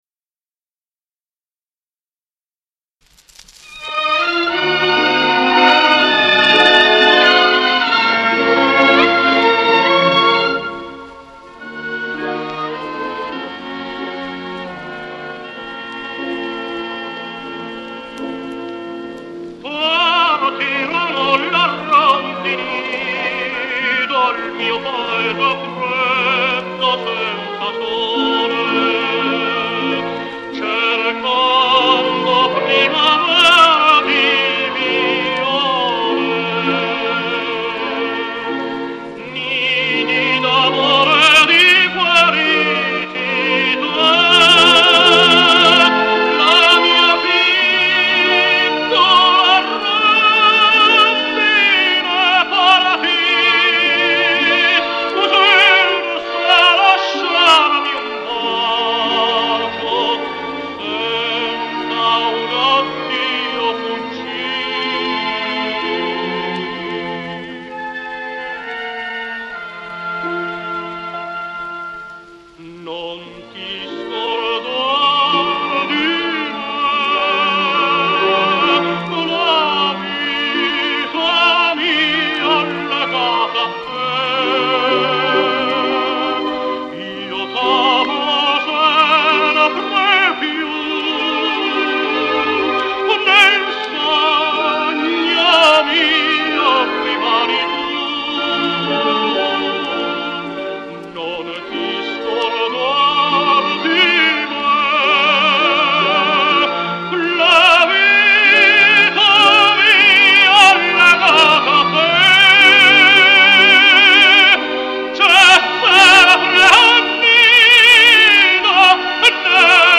con accompagnamento d'orchestra